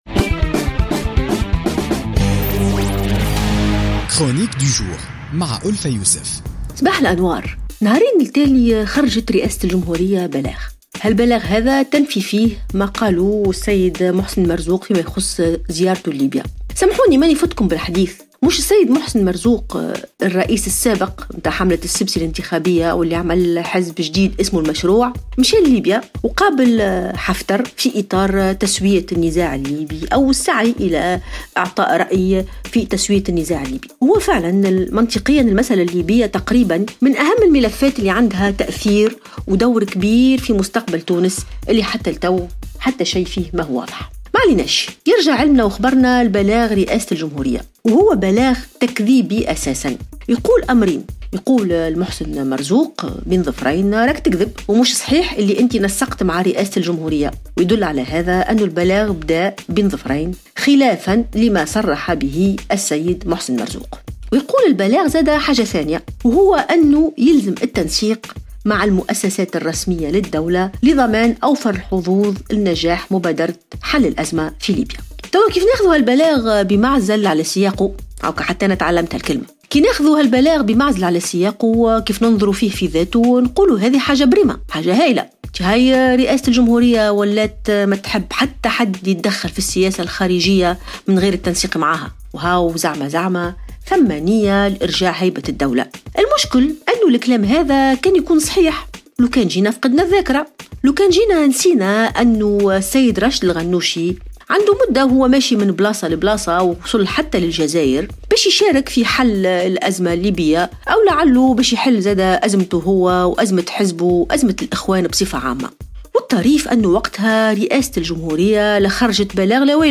تطرقت الكاتبة ألفة يوسف في افتتاحية اليوم الجمعة 24 فيفري 2017 إلى بلاغ رئاسة الجمهورية الذي كذبت فيه تصريحات محسن مرزوق بخصوص زيارته إلى ليبيا .